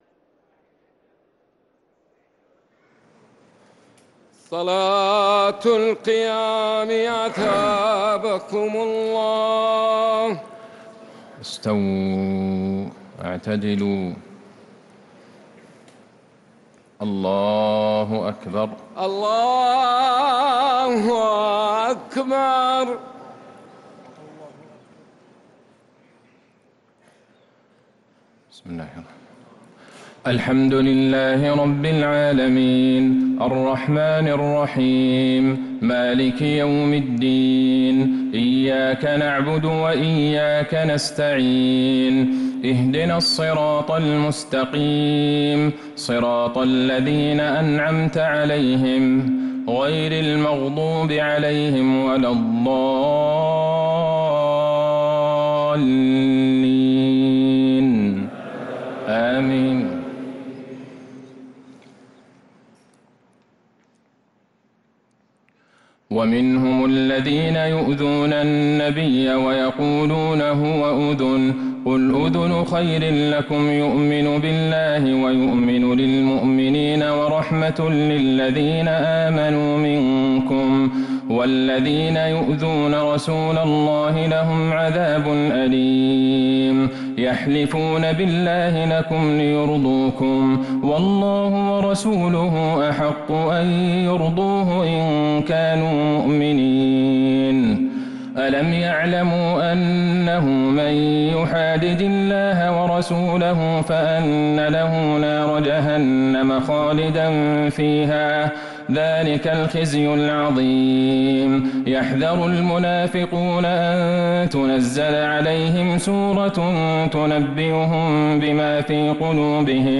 تراويح ليلة 14رمضان 1447هـ من سورة التوبة ( 61-116) | Taraweeh 14th night Ramadan 1447H Surah Al-Tawbah > تراويح الحرم النبوي عام 1447 🕌 > التراويح - تلاوات الحرمين